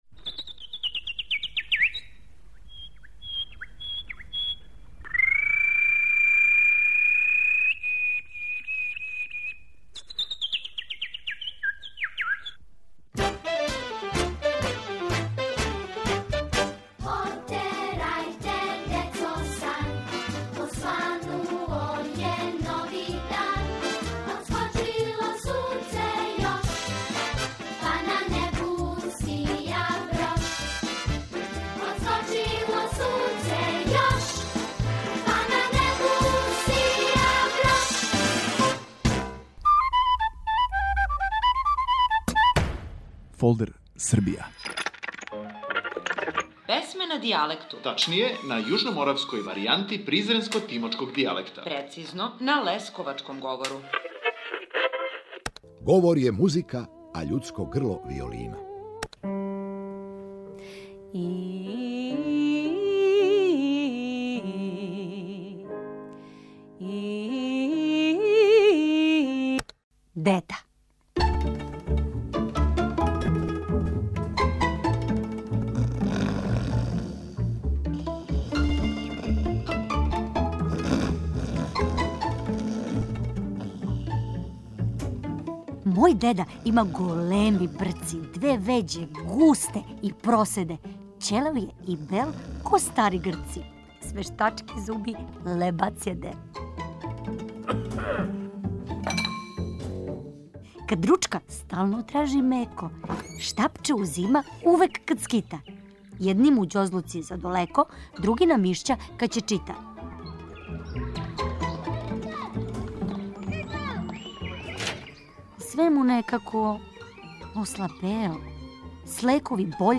на јужноморавској варијанти призренско - тимочког дијалекта, прецизније - на лесковачком говору.